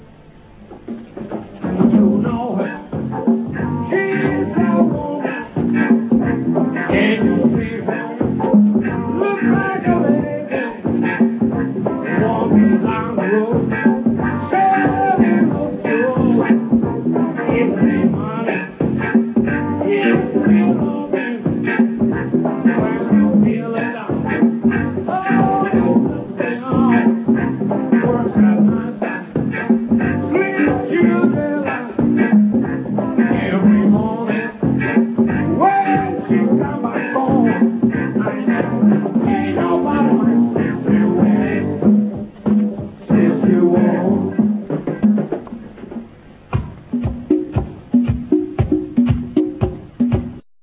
Video presentations of this 70s disco funk band: